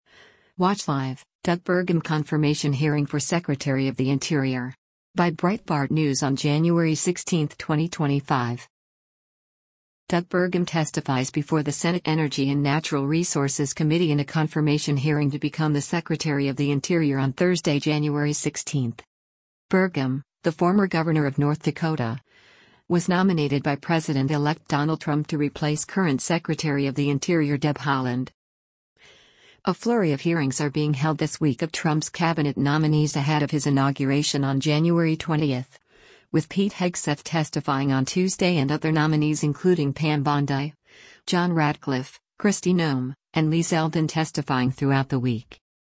Doug Burgum testifies before the Senate Energy and Natural Resources Committee in a confirmation hearing to become the Secretary of the Interior on Thursday, January 16.